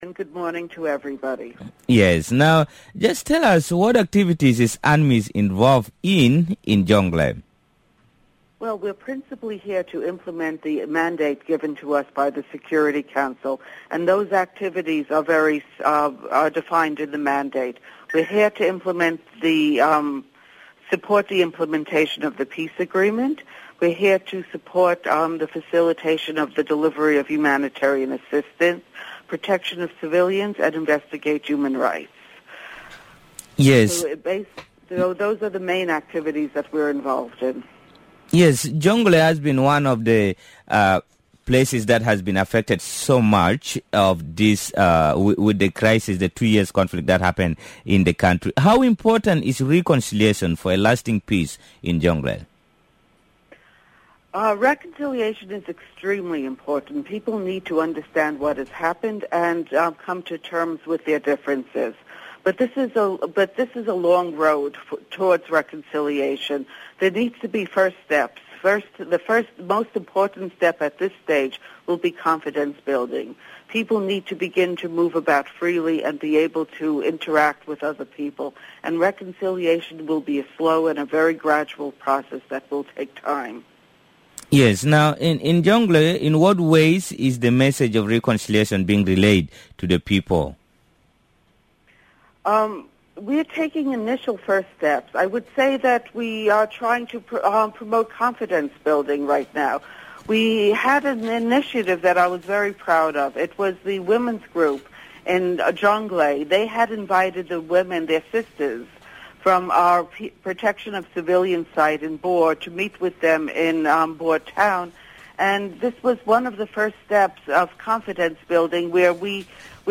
Speaking to the Breakfast show on Radio Miraya